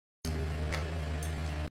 Car Crash Loop Sound Effects Free Download